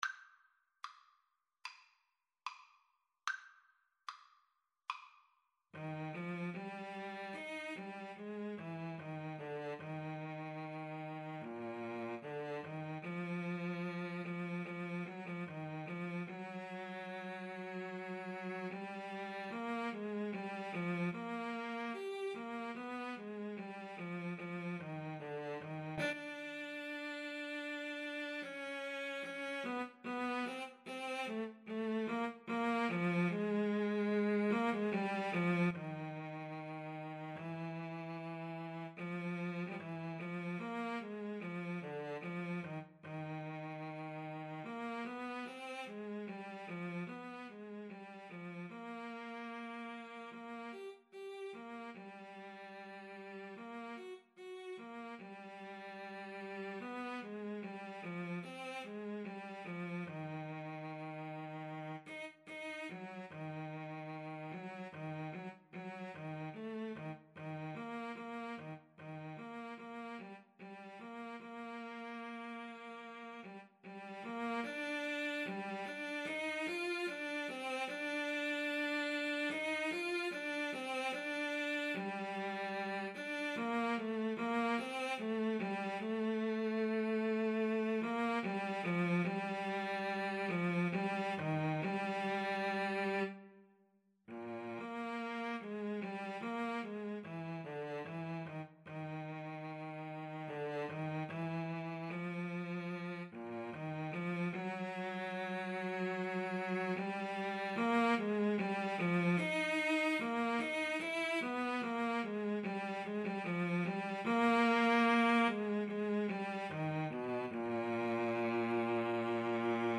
Play (or use space bar on your keyboard) Pause Music Playalong - Player 1 Accompaniment reset tempo print settings full screen
Eb major (Sounding Pitch) (View more Eb major Music for Cello Duet )
=74 Andante moderato (View more music marked Andante Moderato)